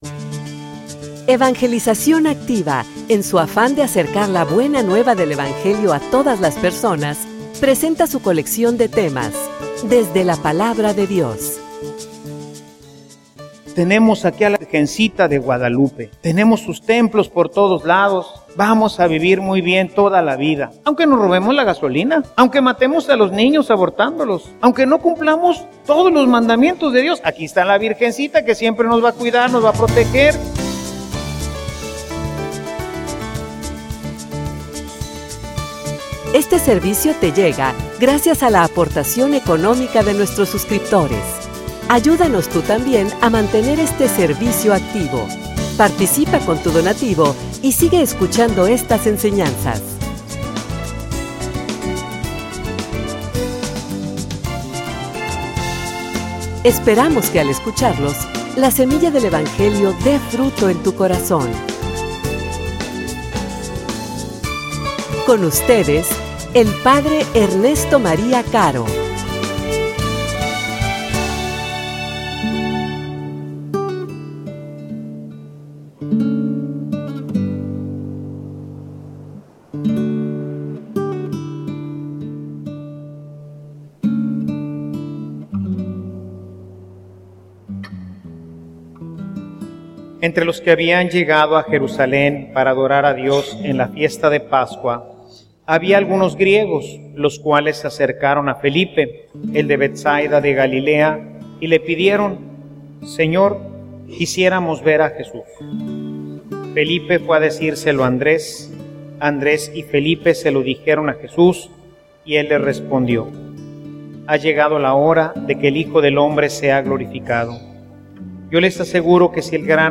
homilia_Cumple_la_alianza.mp3